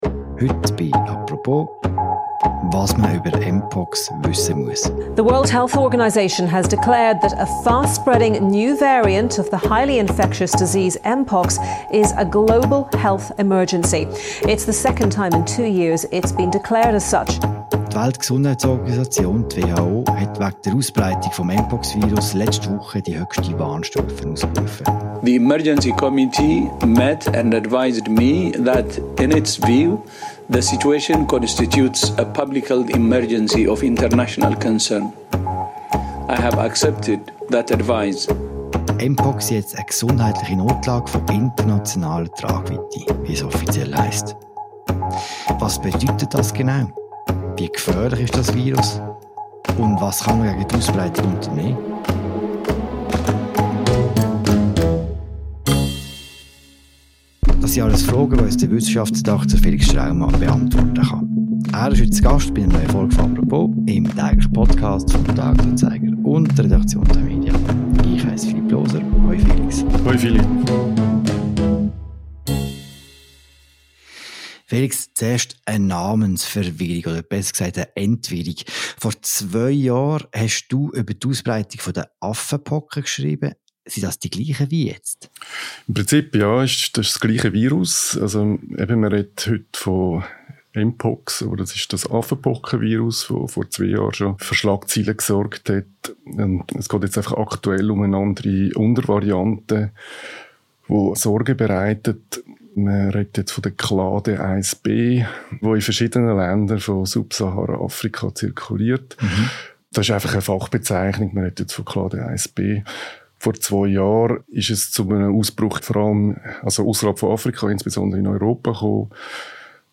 Er ist zu Gast in einer neuen Folge des täglichen Podcasts «Apropos».